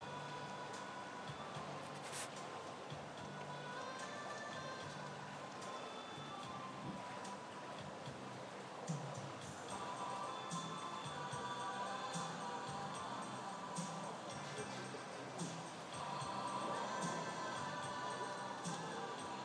The sound of the poolside, Dubrovnik...